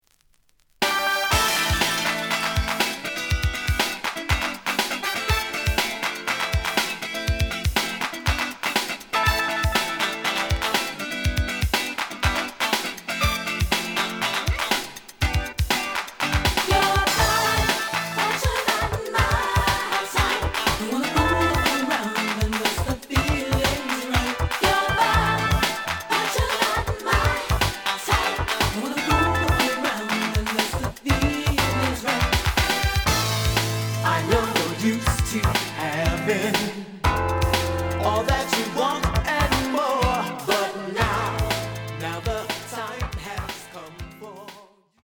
The audio sample is recorded from the actual item.
●Genre: Disco
●Record Grading: VG~VG+ (傷はあるが、プレイはおおむね良好。Plays good.)